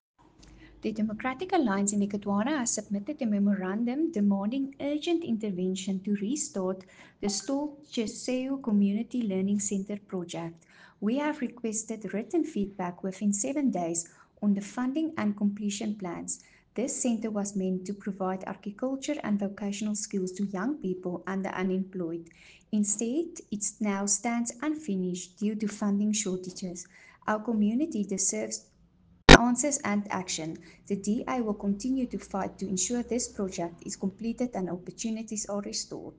Afrikaans soundbites by Cllr Anelia Smit and Sesotho soundbite by Cllr Diphapang Mofokeng.
Tjheseho-Community-Learning-Centre-project-ENG-.mp3